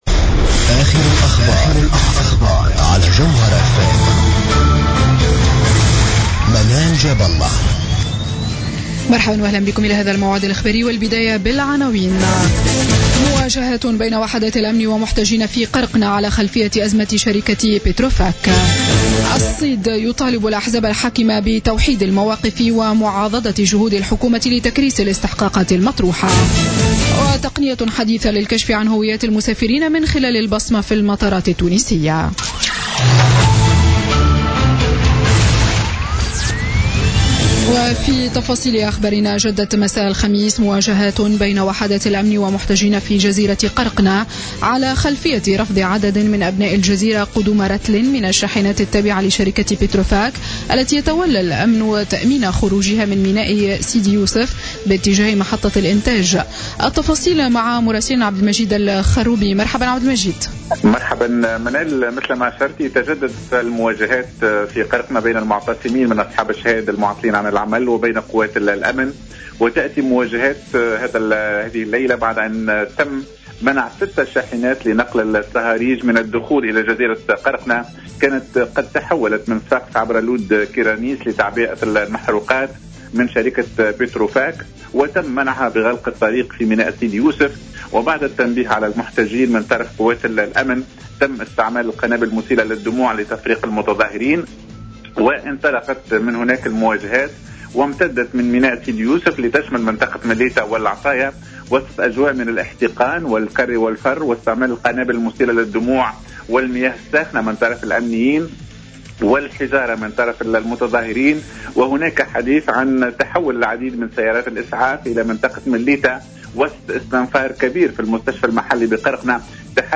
نشرة أخبار منتصف الليل ليوم الجمعة 15 أفريل 2016